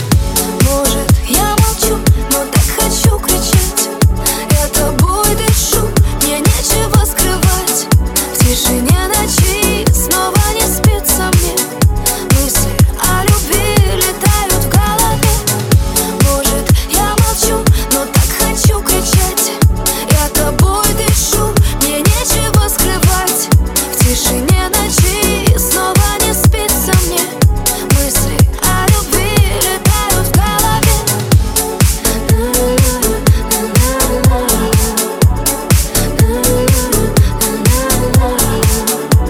• Качество: 320, Stereo
Dance Pop